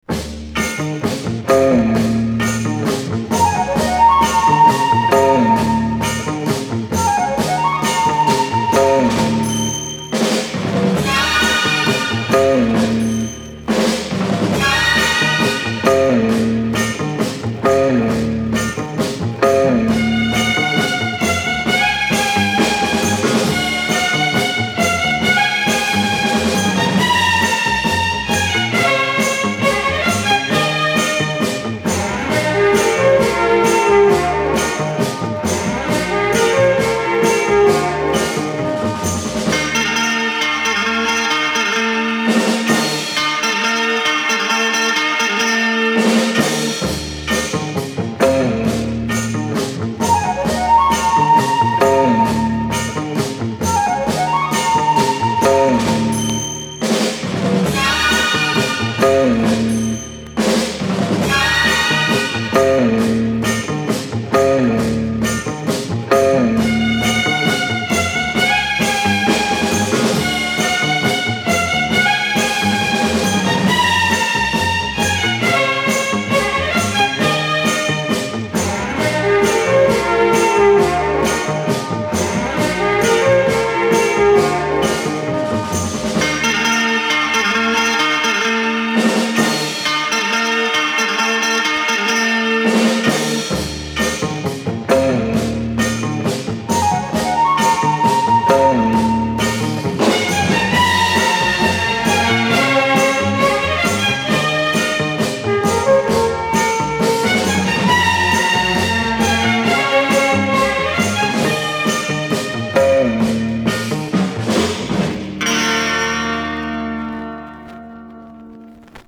Французский флейтист, пианист и композитор.